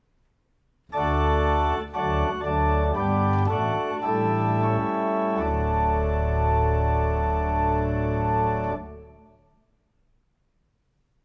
Segen und dreifaches AMEN in D-Dur (!)
Liturgie-AMEN-dreifach-in-D-Dur.mp3